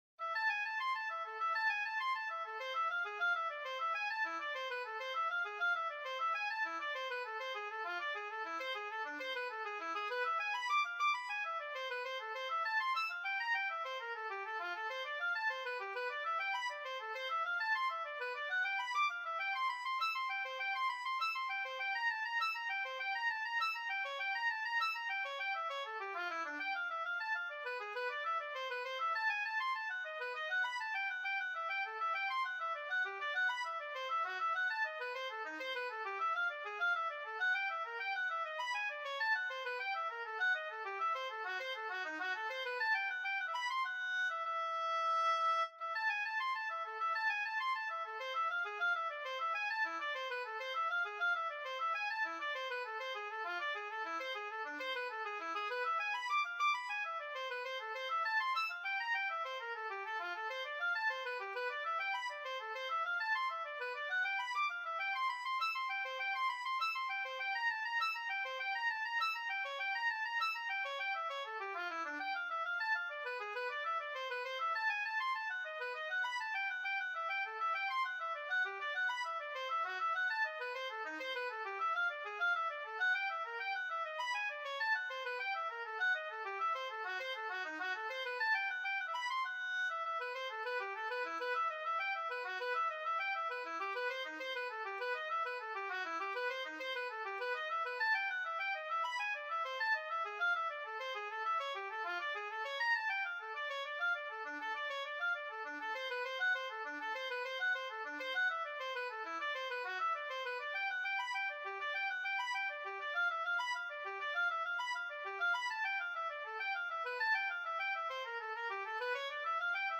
Bach, Johann Sebastian - Partita in A minor, BWV 1013 Free Sheet music for Oboe
Tempo Marking: Allemande
Instrument: Oboe
Style: Classical